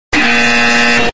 Lie Detector Wrong Sound Effect Free Download
Lie Detector Wrong